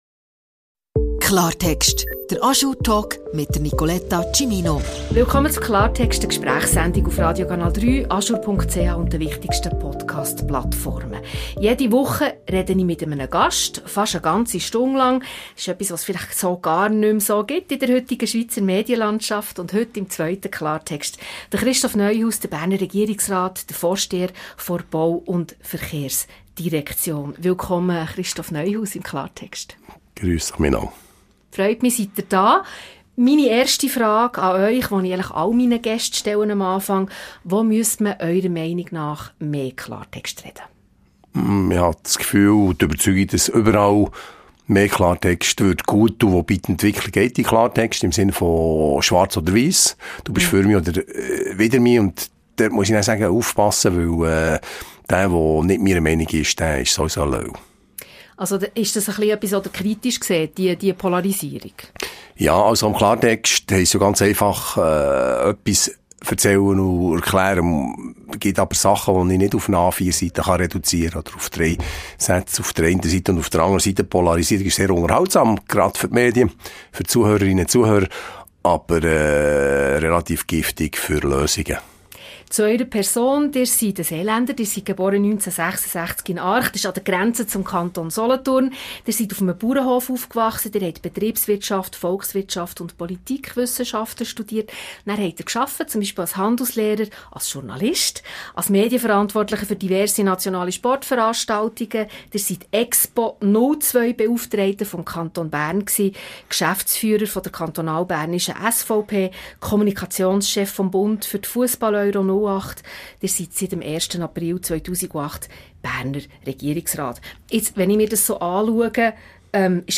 Der Berner SVP-Regierungsrat Christoph Neuhaus ist zu Gast im "Klartext". Er spricht über seine Kindheit im seeländischen Arch, seine Familie und seine Faszination für die USA.